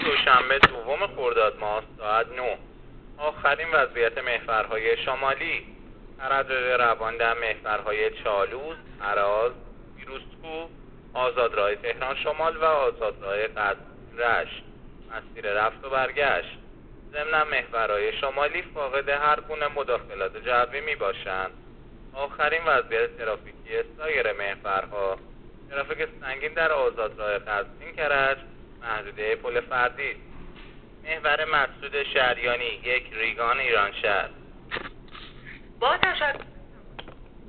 گزارش رادیو اینترنتی از آخرین وضعیت ترافیکی جاده‌ها تا ساعت ۹ دوم خرداد؛